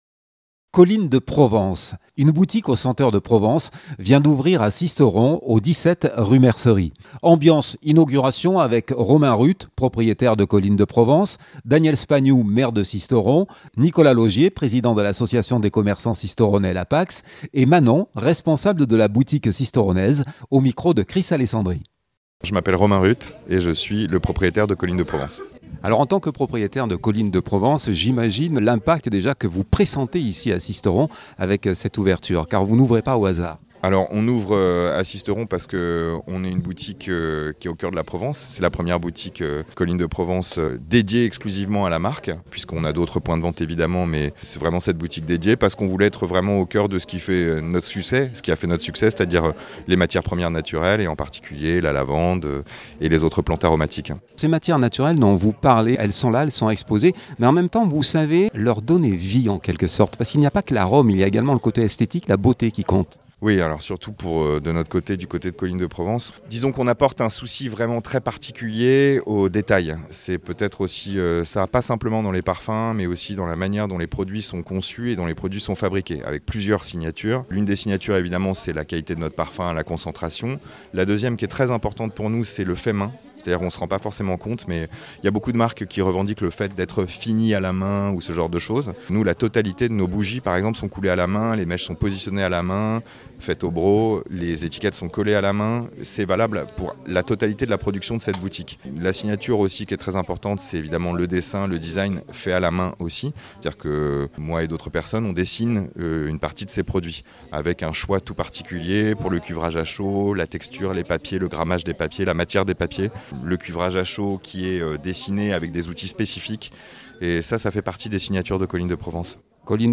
Une boutique aux senteurs de Provence vient d’ouvrir à Sisteron, 17 rue Mercerie. Ambiance inauguration